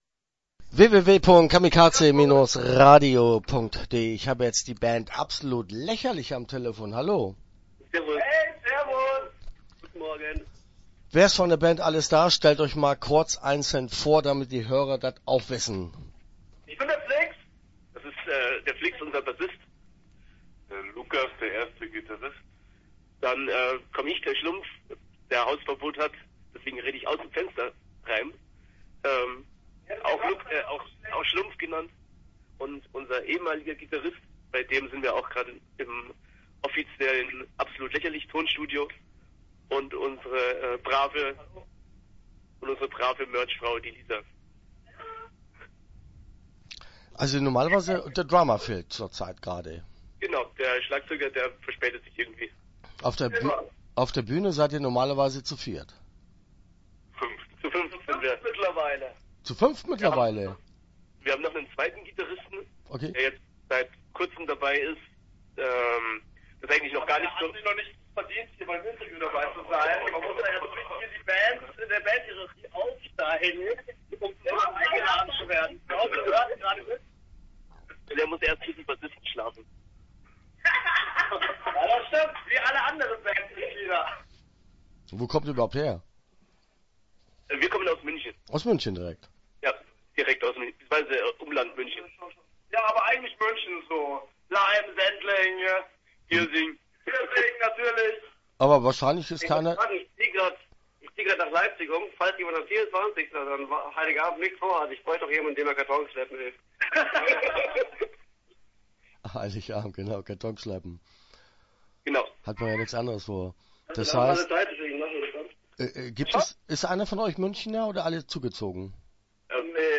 Start » Interviews » Absolut Lächerlich